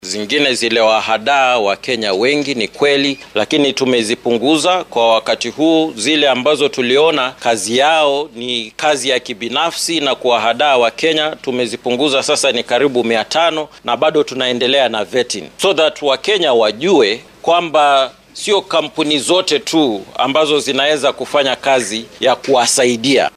Shir jaraaid oo uu maanta ku qabtay magaalada Nairobi ayuu xoghayaha ka sheegay inay socdaan baaritaano ka dhan ah shirkadahan.